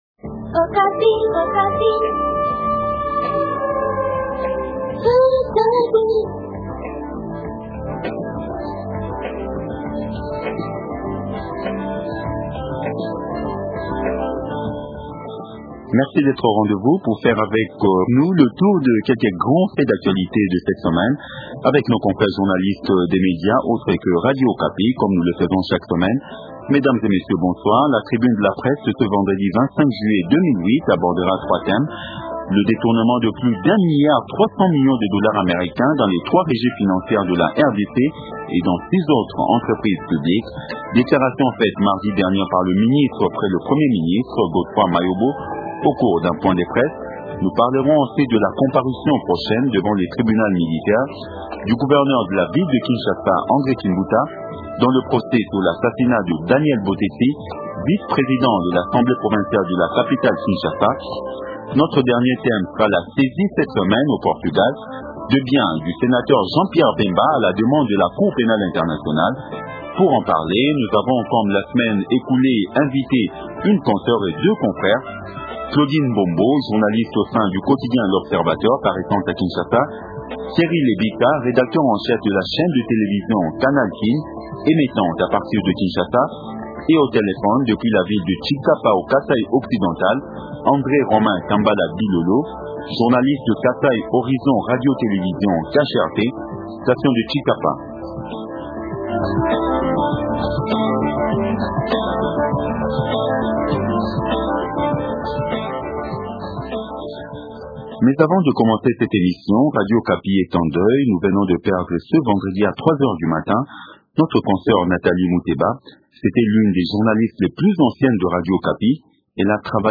qui va intervenir au téléphone à partir de la ville de Tshikaparn